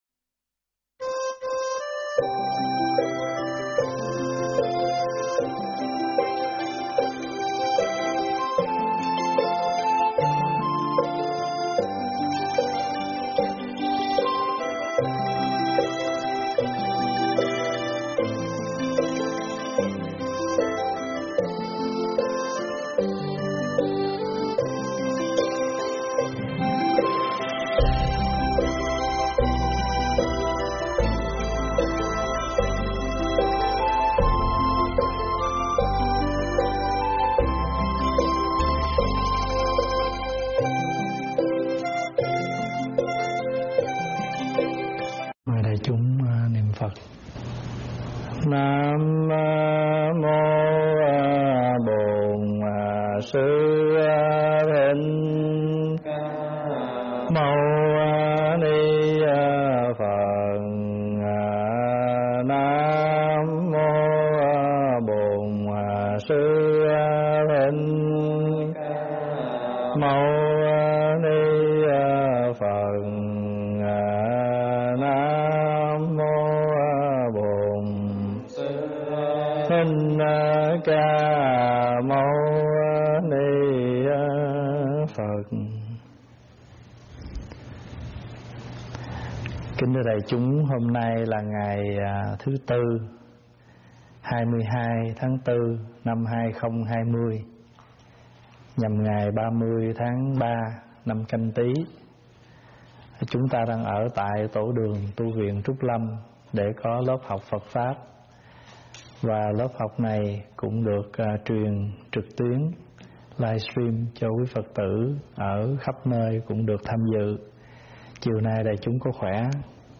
Nghe mp3 thuyết pháp Từng Giọt Sữa Thơm 8
giảng tại Tv Trúc Lâm